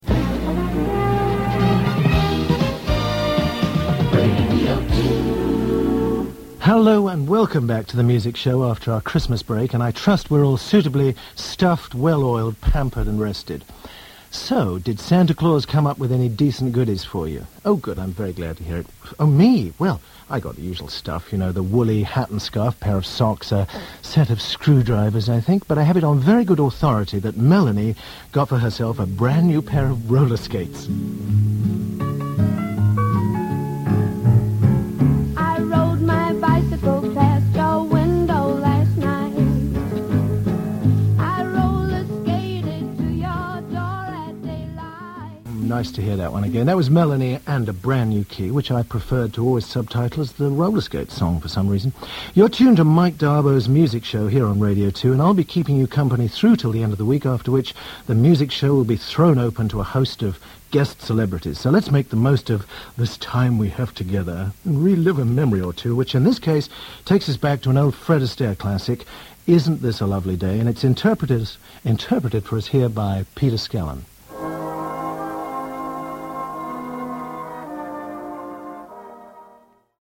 Mike D'Abo, one time singer with Manfred Mann, takes over the David Hamilton Music Show for a couple of weeks over Christmas and New Year 1986.